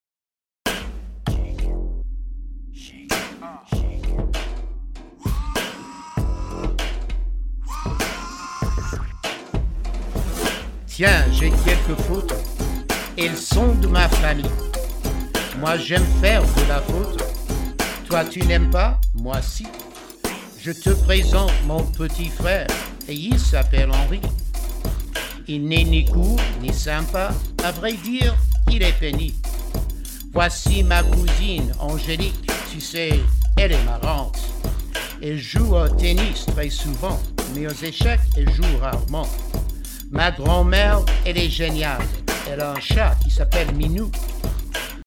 French Language Raps